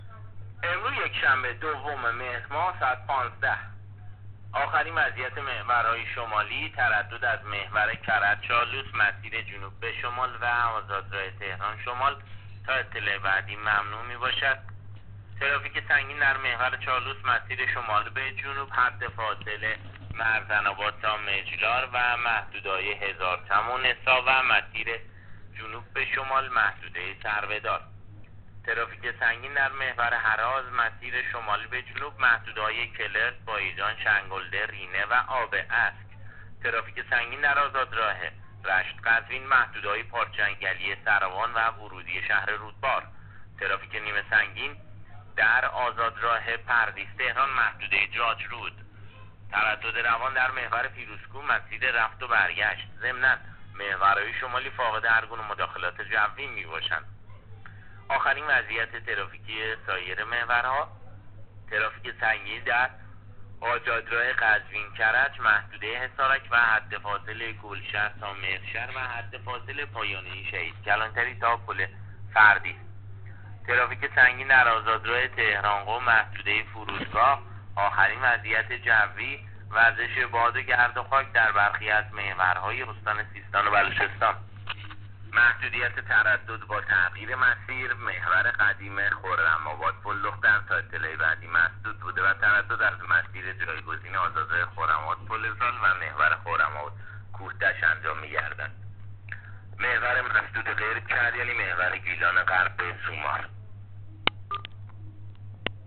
گزارش رادیو اینترنتی از آخرین وضعیت ترافیکی جاده‌ها تا ساعت ۱۵ دوم مهر؛